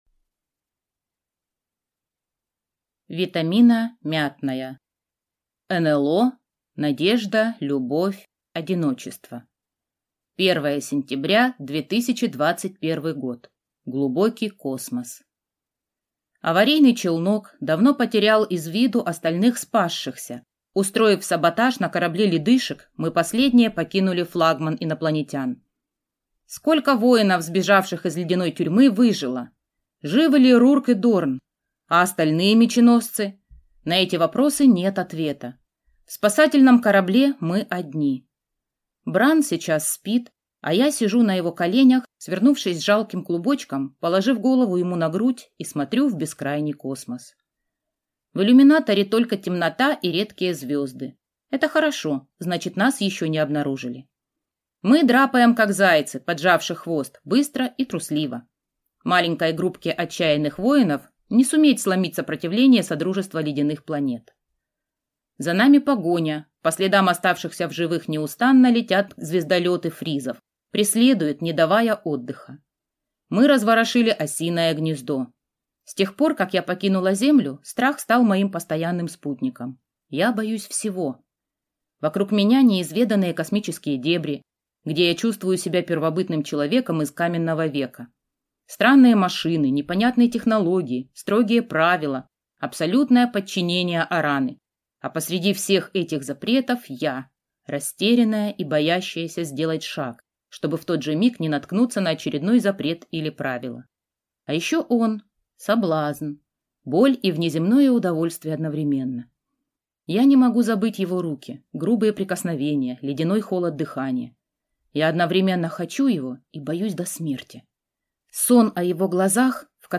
Аудиокнига НЛО – надежда любовь одиночество | Библиотека аудиокниг